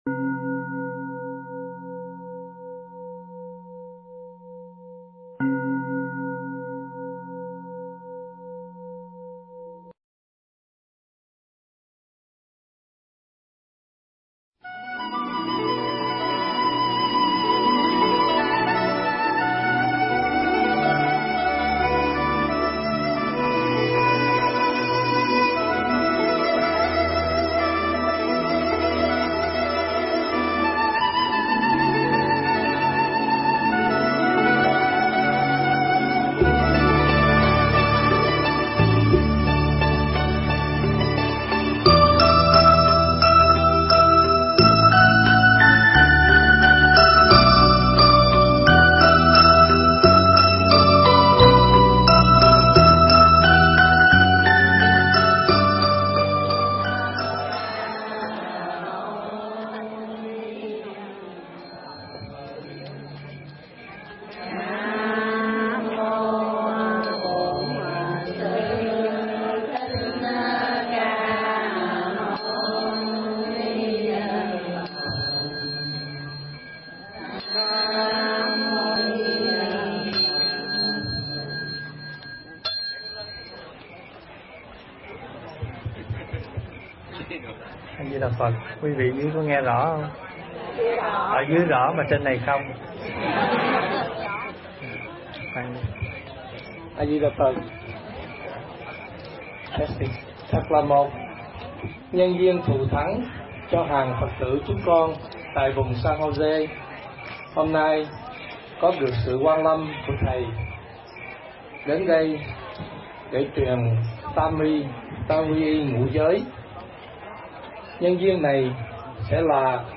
Vấn Đáp
thuyết pháp